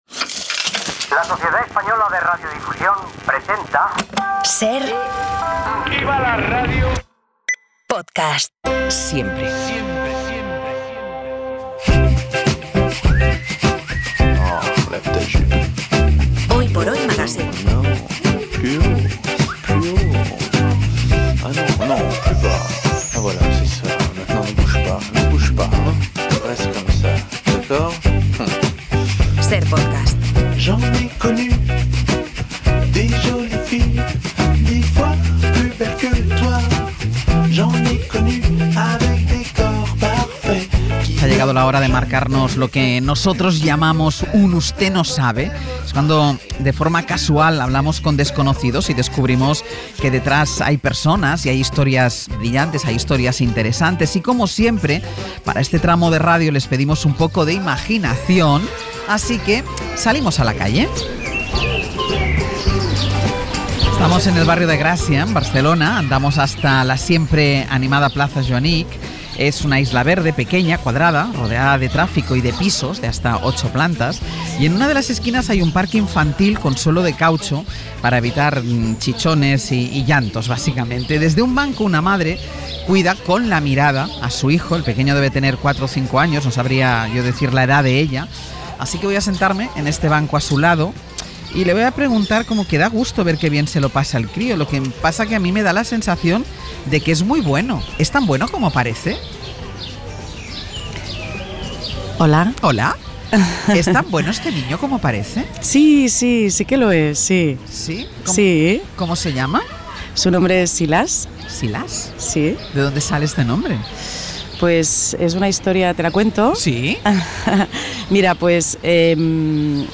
Hoy nos sentamos en un banco de Barcelona